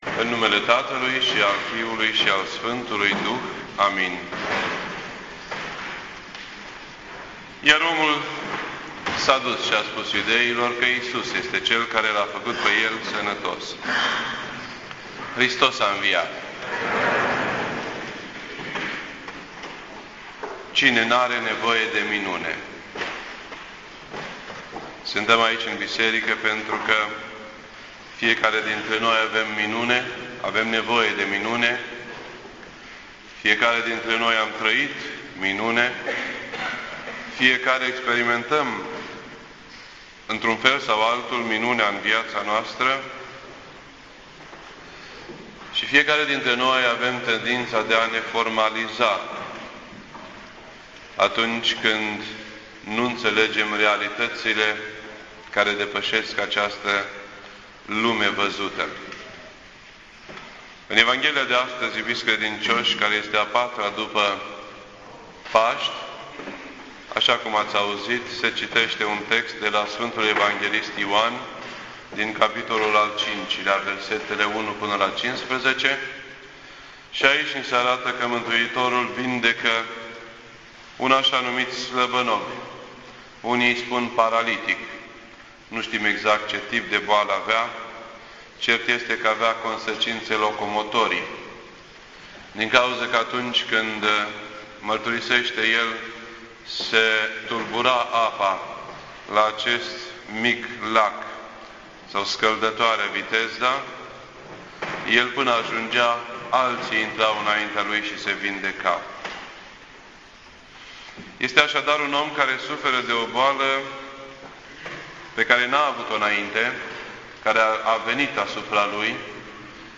This entry was posted on Sunday, May 15th, 2011 at 7:08 PM and is filed under Predici ortodoxe in format audio.